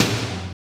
SEATOM LO.wav